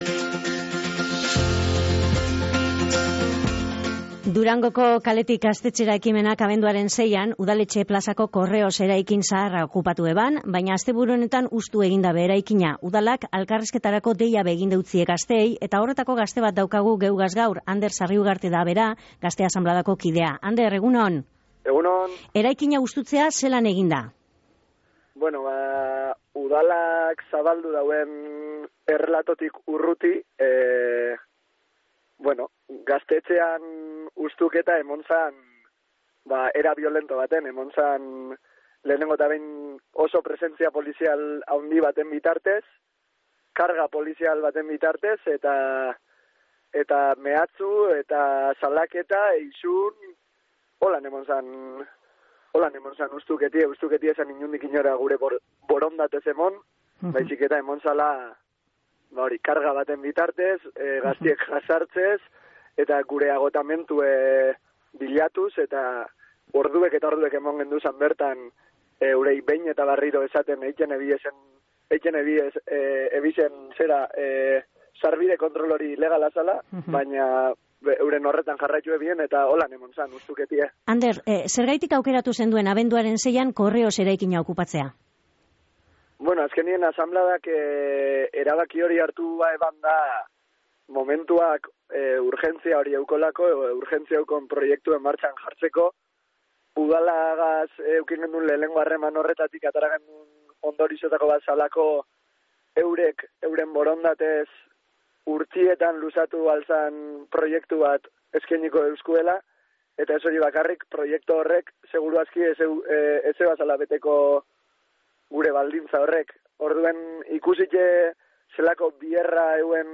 "Hutsitu daben eraikina udalak bildegi lez erabilten dau" esan dau Gazte Asanbladako kideak